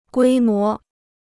规模 (guī mó): scale; scope.